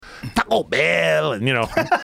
Play, download and share Taco Bell original sound button!!!!